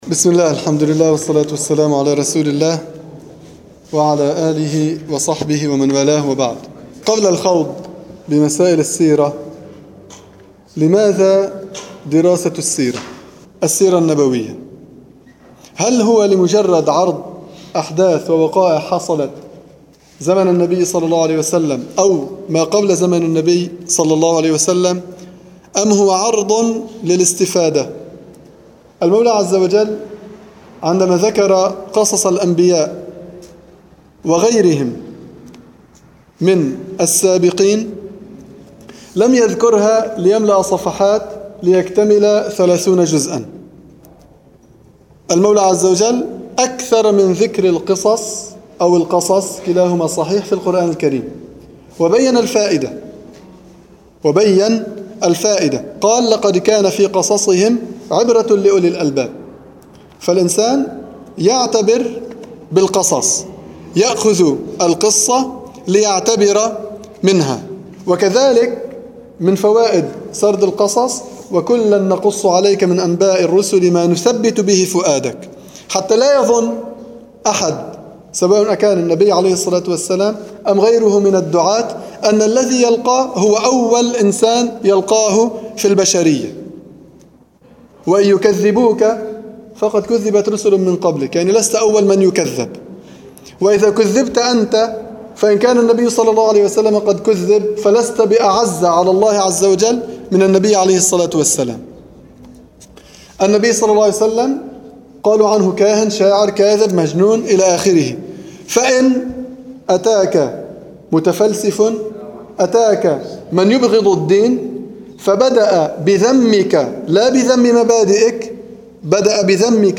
سلسلة دروس السيرة النبوية
في مسجد القلمون الغربي